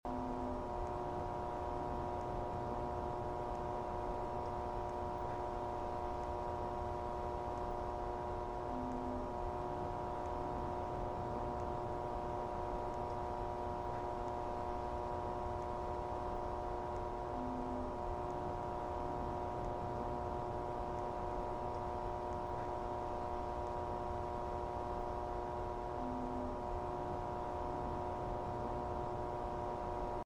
Backrooms is an sound light sound effects free download
Backrooms is an sound light buzzing in which VHS